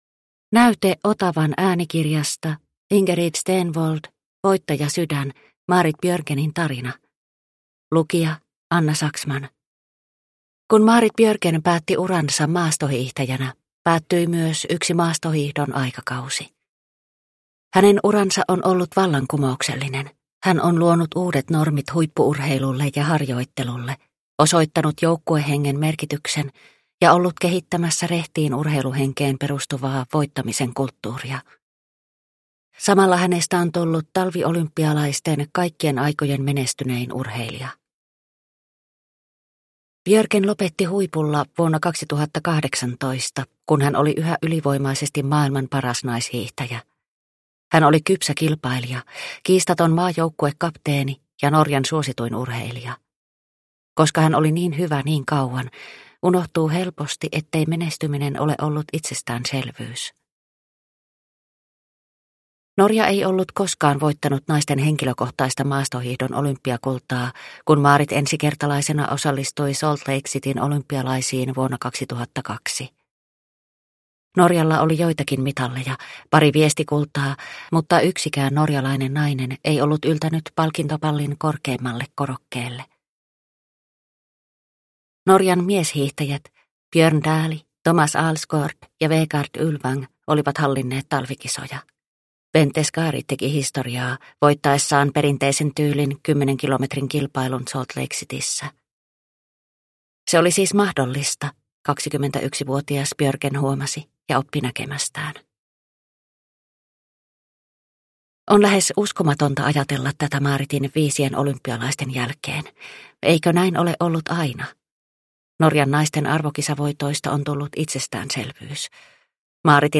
Voittajasydän – Ljudbok